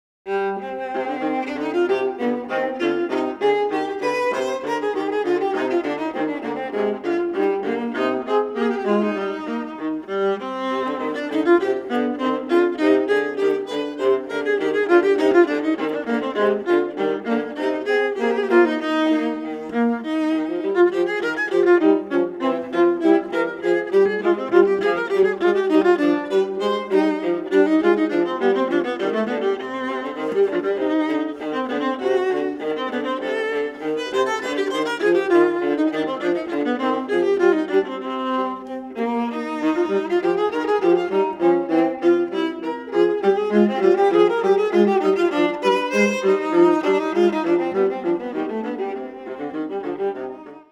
Pieces for two violas.
LISTEN 6 Bourree
Recorded at: Music Works Recordillg Studios,